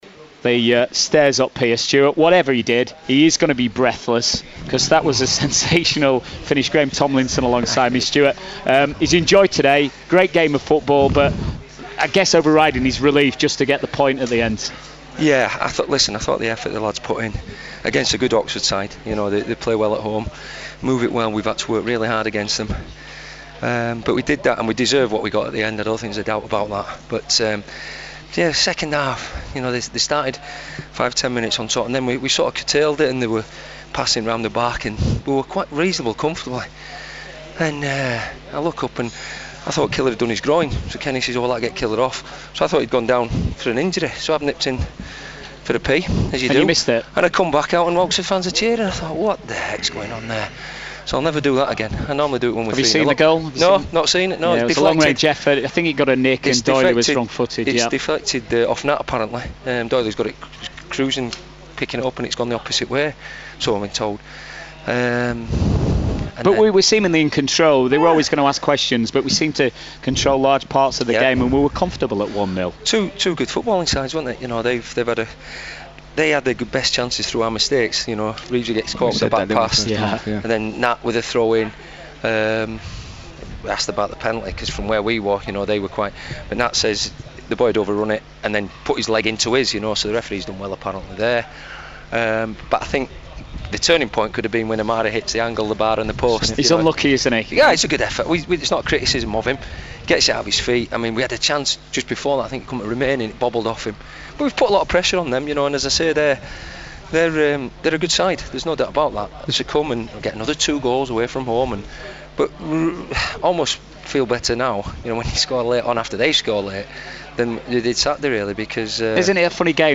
Stuart McCall Post Match interview vs Oxford